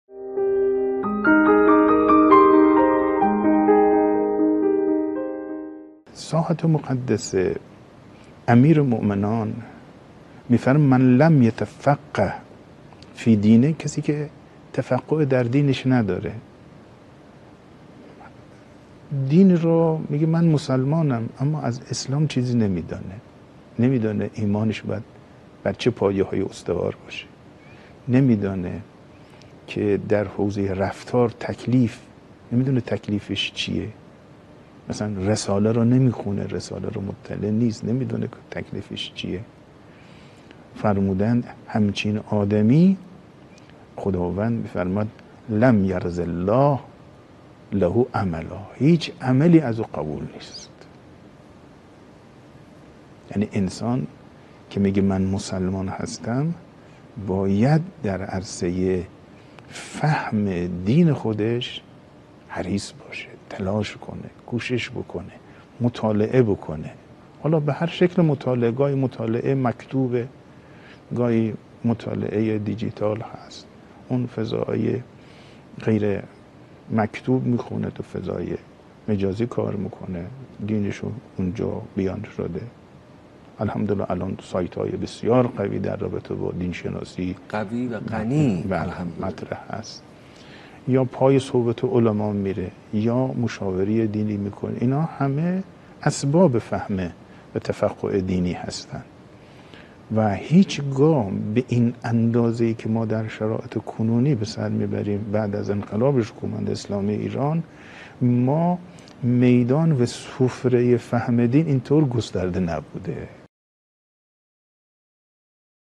سخنرانی آیت‌الله صفایی‌بوشهری